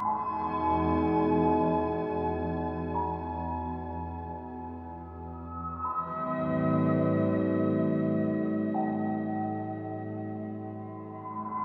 RIDING SMOOTH 165 BPM - FUSION.wav